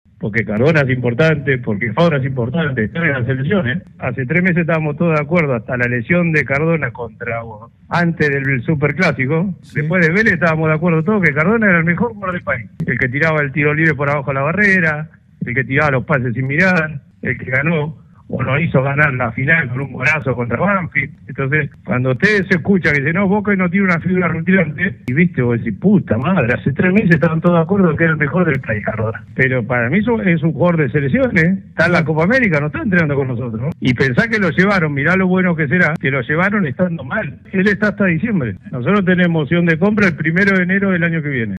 Juan Román Riquelme, vicepresidente de Boca Juniors en ESPN.